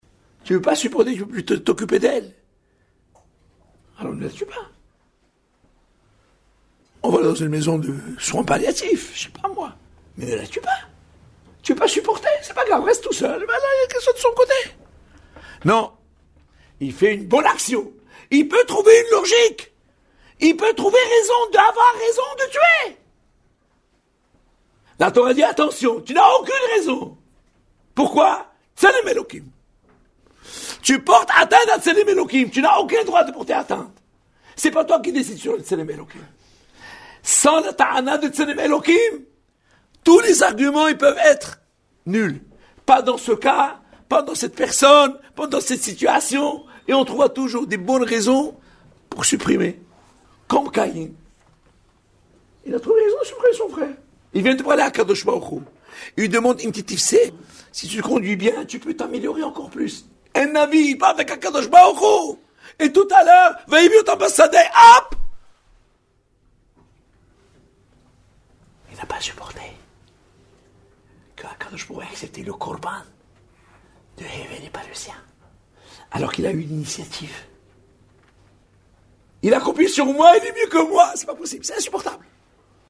exposé